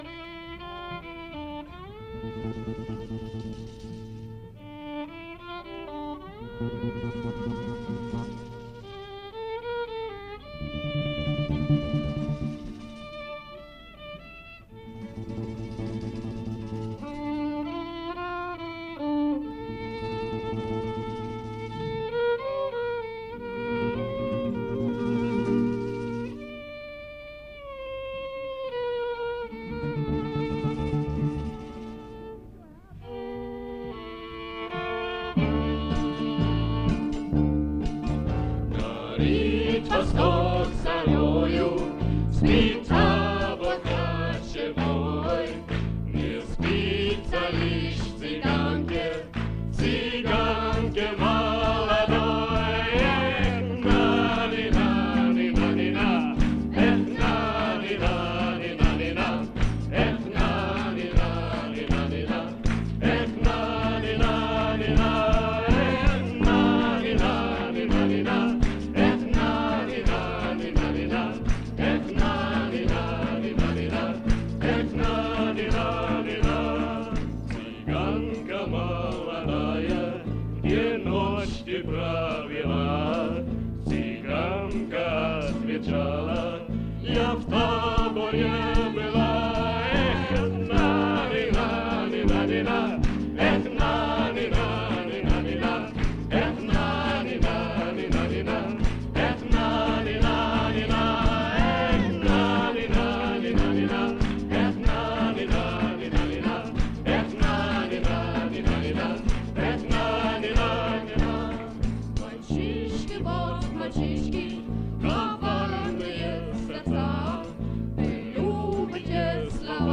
Цыганка1984 SUSI - SU Singe / Moskau / Rucksack-Programm 12Радуга/RadugaМЭИ1984